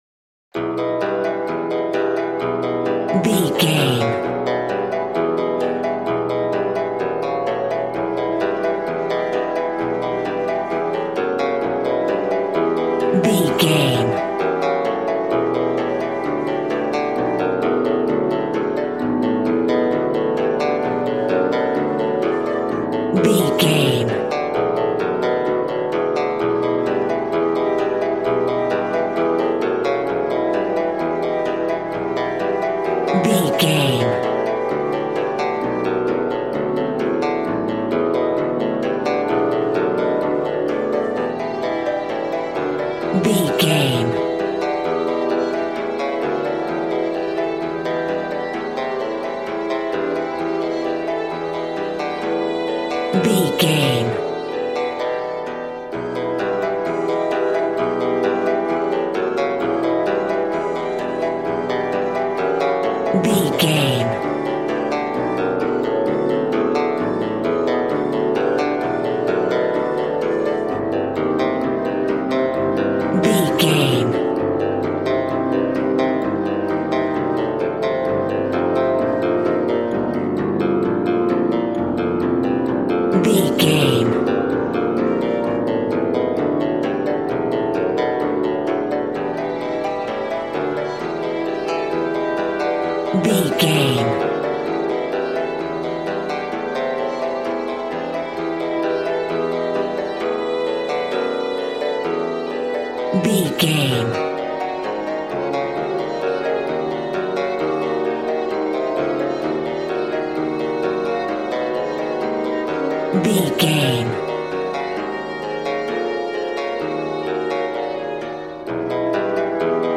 Aeolian/Minor
smooth
conga
drums